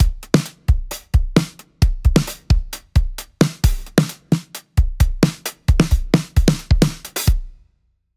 スネアの1/8＆1/16ずらし
かなり複雑に聴こえますよね。
r1-synco-drum-snare16th.mp3